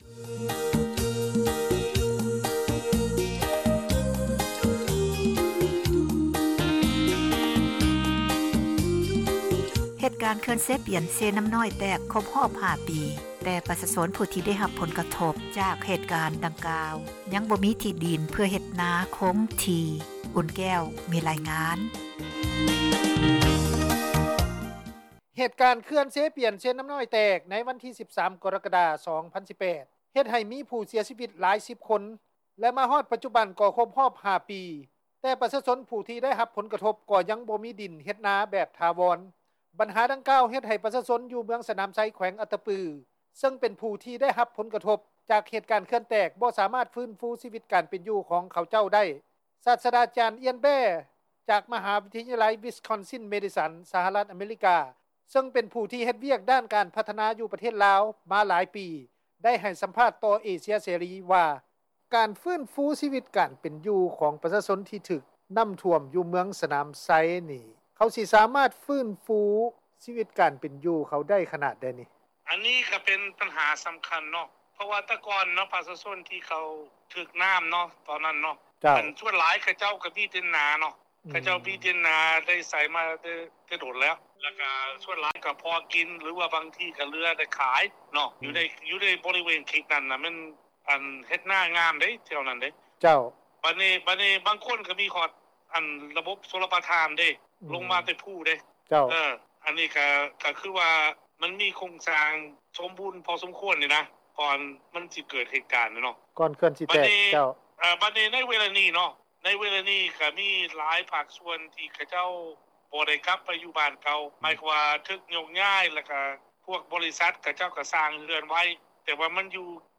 ເຂື່ອນແຕກ 5 ປີ, ຊາວບ້ານ ຍັງບໍ່ມີອາຊີບຖາວອນ — ຂ່າວລາວ ວິທຍຸເອເຊັຽເສຣີ ພາສາລາວ